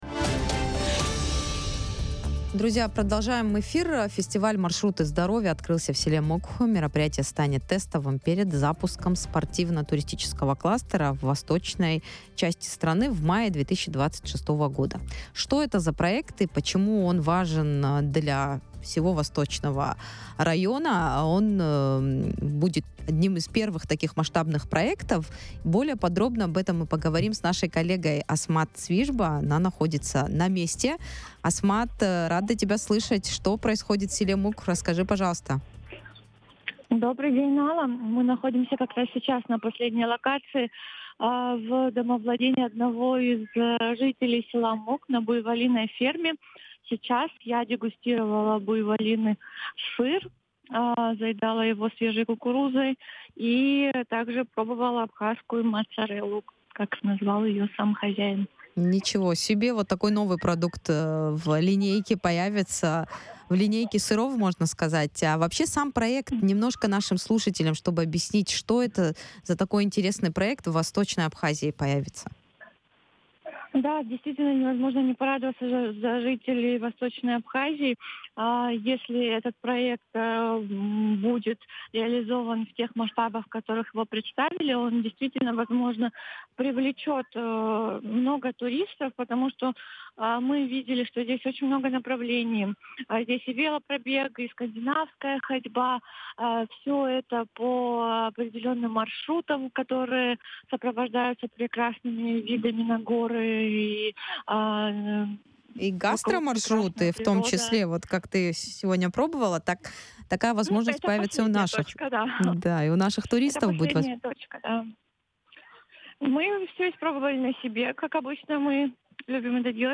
Фестиваль "Маршруты здоровья": интервью с места события